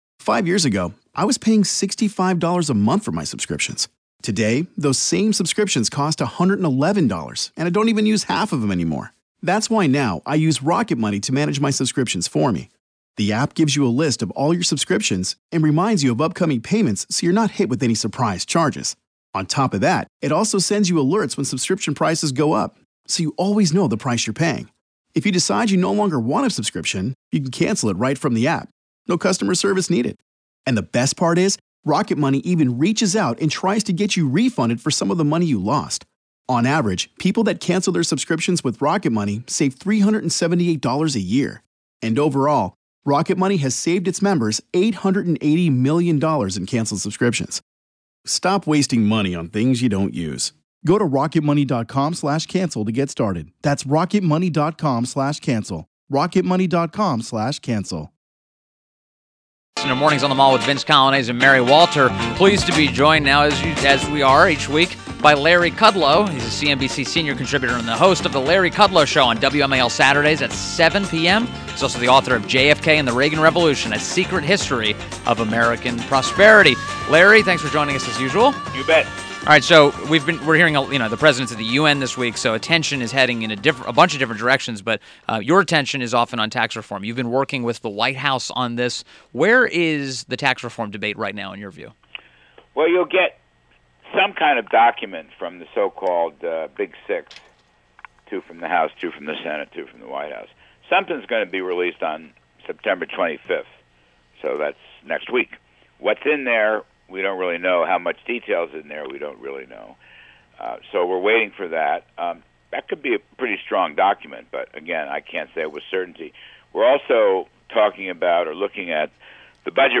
WMAL Interview - LARRY KUDLOW - 09.19.17